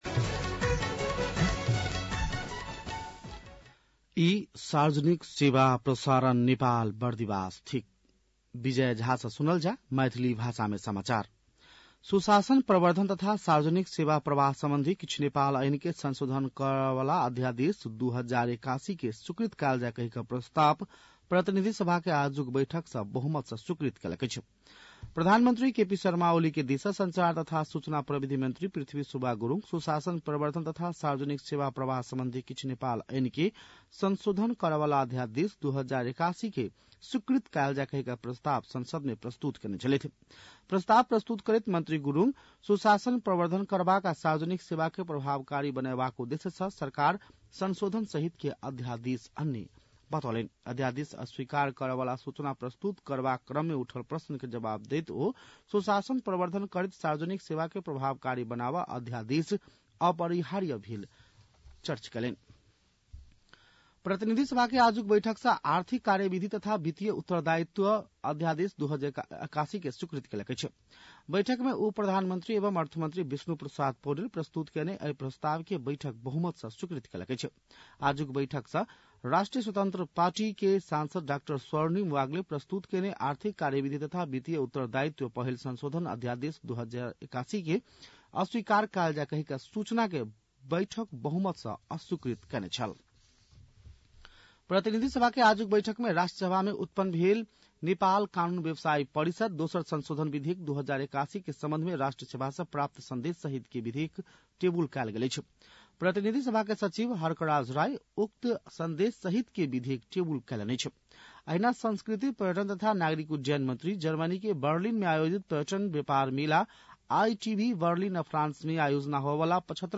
मैथिली भाषामा समाचार : २२ फागुन , २०८१
Maithali-news-11-21.mp3